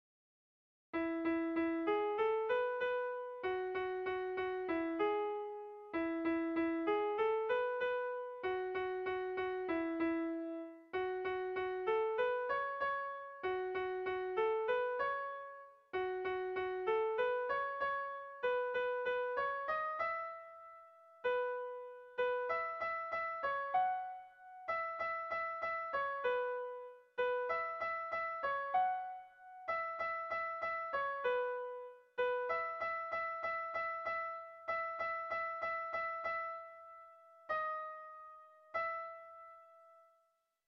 Tragikoa
ABD..